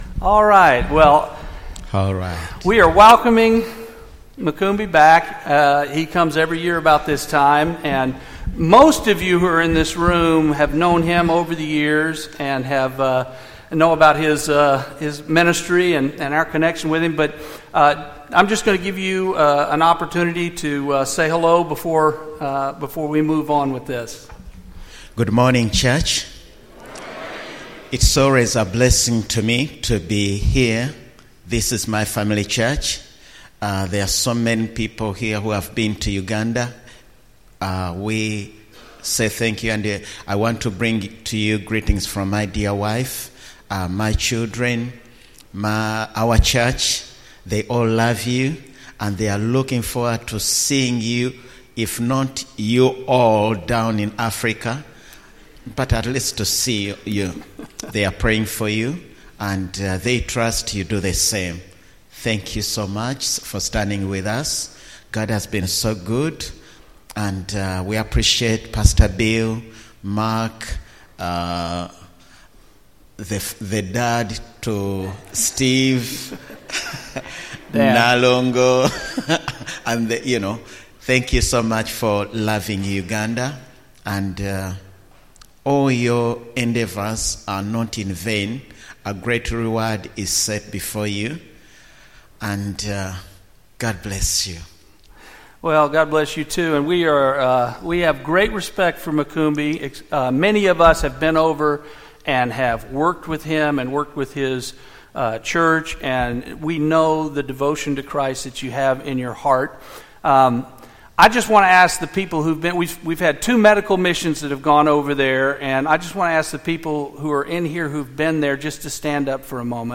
Personal Testimonies